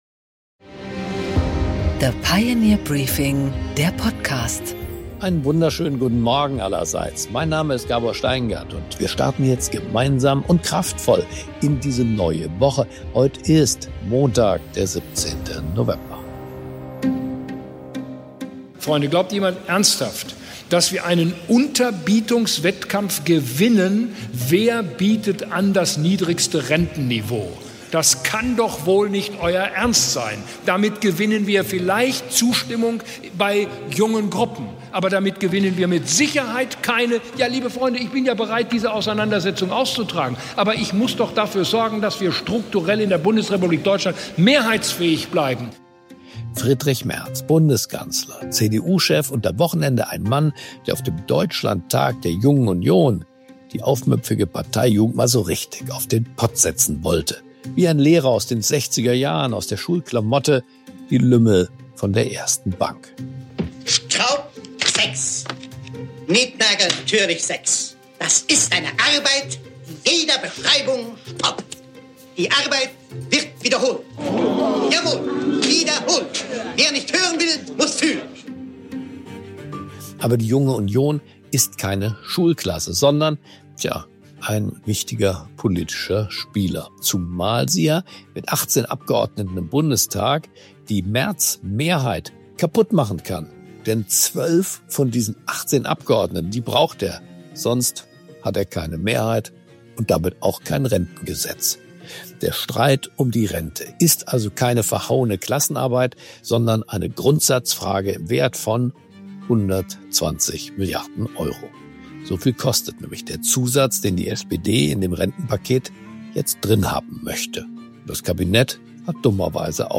Gabor Steingart präsentiert das Pioneer Briefing.
Im Gespräch: Johannes Winkel